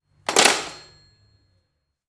Colgar el auricular de un teléfono de los años 30